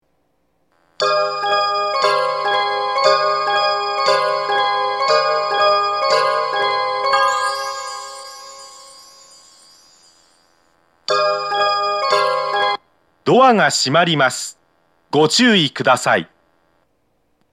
発車メロディーはテイチク製の曲で、音質が非常に良いです。
発車メロディー 1番線とは対照的に、曲も長くダイヤも厳しめなので途中切りが大変多いです。